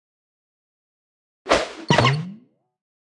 音效
Media:Sfx_Anim_Baby_Chicken.wavMedia:Sfx_Anim_Classic_Chicken.wavMedia:Sfx_Anim_Super_Chicken.wavMedia:Sfx_Anim_Ultra_Chicken.wavMedia:Sfx_Anim_Ultimate_Chicken.wav 动作音效 anim 在广场点击初级、经典、高手、顶尖和终极形态或者查看其技能时触发动作的音效
Sfx_Anim_Baby_Chicken.wav